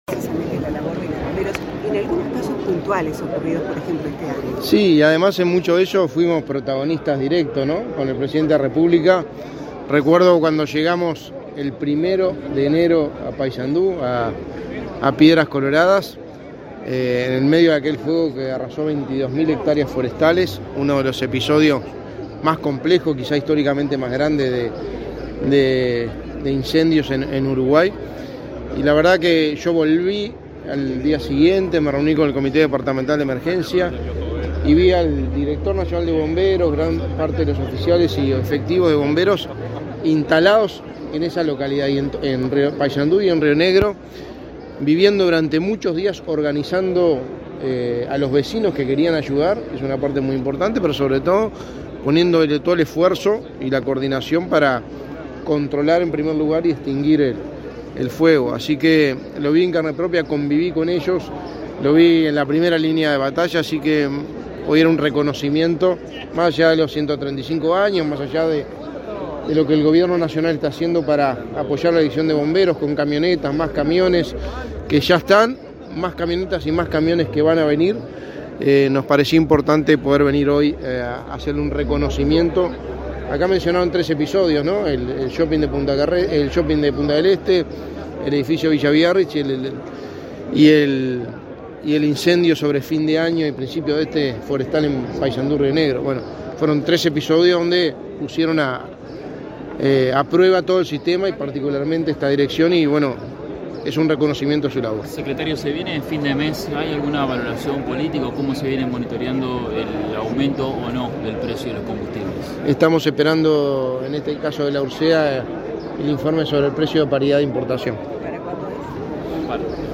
Luego dialogó con la prensa.